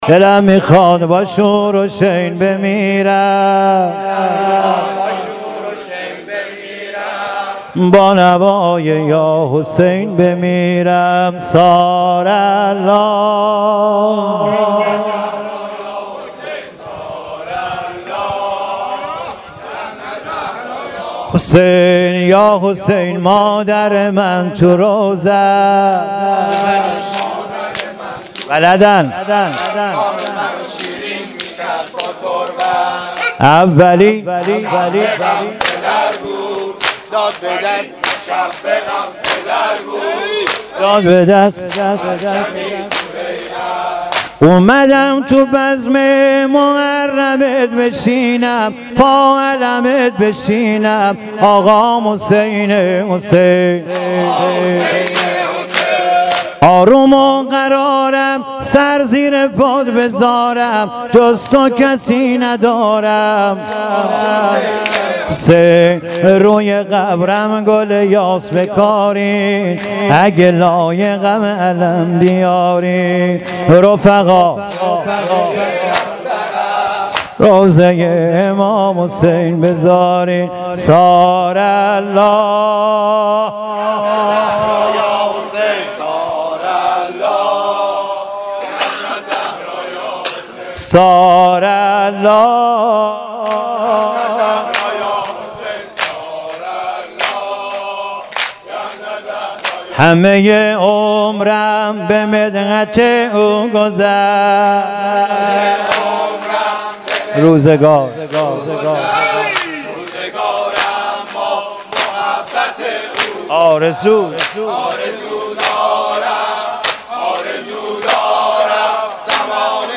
شهریور 1396 - حسینیه میرسرروضه